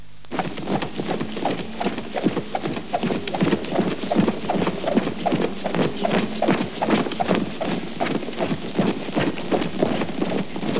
home *** CD-ROM | disk | FTP | other *** search / Horror Sensation / HORROR.iso / sounds / iff / vampire.snd ( .mp3 ) < prev next > Amiga 8-bit Sampled Voice | 1992-12-21 | 215KB | 1 channel | 19,886 sample rate | 10 seconds
vampire.mp3